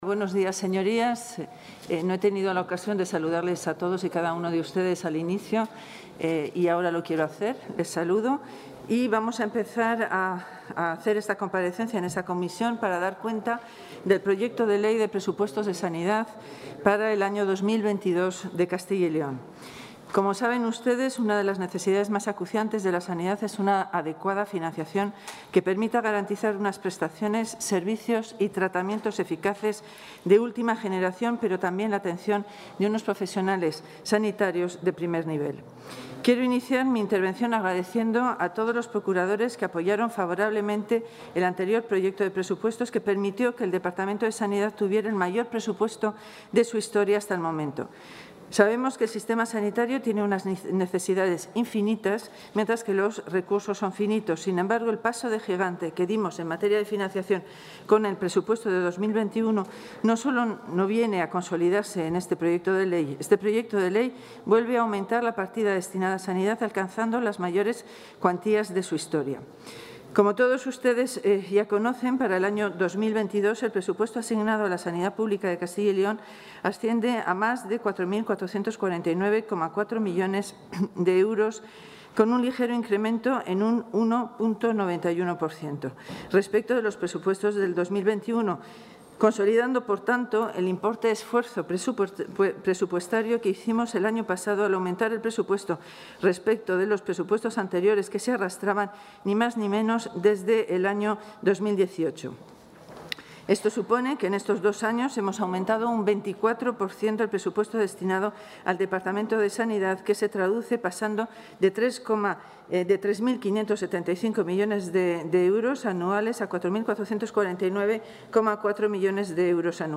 La consejera de Sanidad, Verónica Casado, ha presentado hoy en las Cortes de Castilla y León el Proyecto de Ley de Presupuestos de su...
Comparecencia de la consejera de Sanidad.